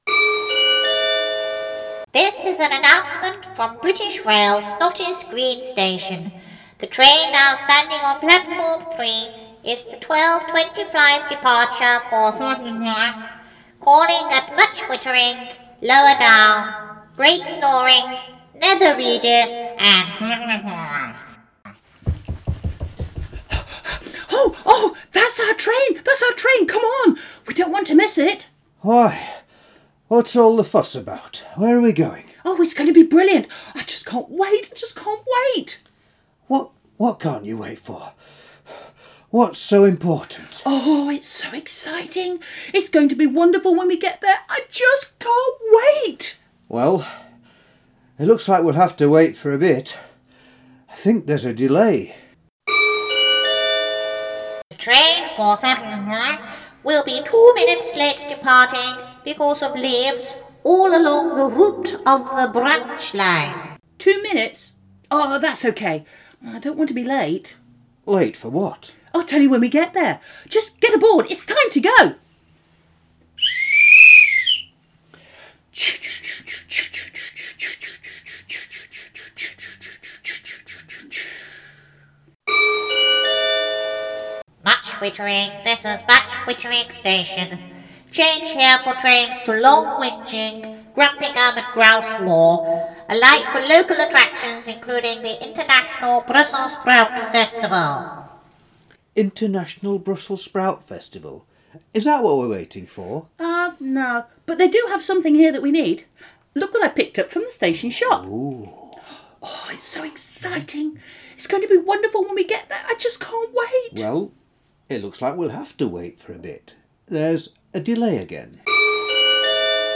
Cast:      Bob, Betty, PA
PA speaks with a ‘pinched nose’ voice and manages props. The [mumble] sections are ‘Snottis Green’ said very indistinctly.
Audience can join in with train sound effects too.